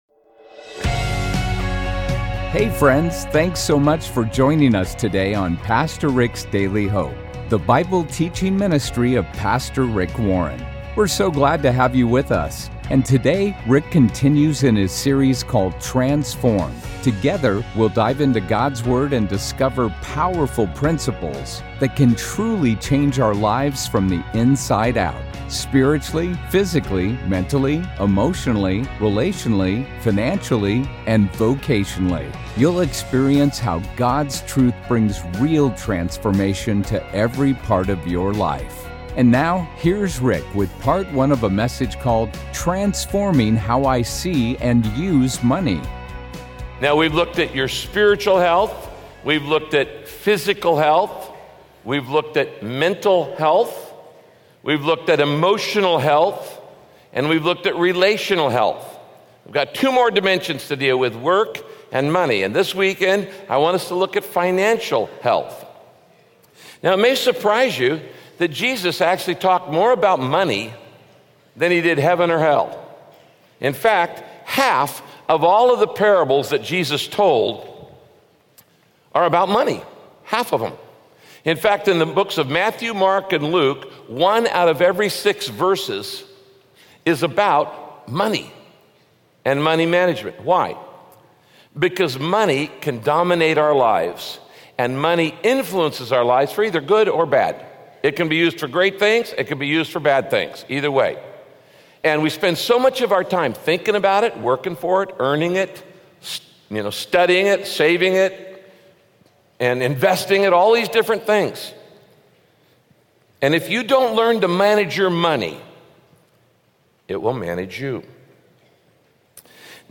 God uses money to test you. He wants to see if you can manage material things before he gives you spiritual blessings. Listen to this message by Pastor Rick and learn how to use your money to honor God—no matter how much or how little you have.